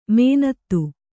♪ mēnattu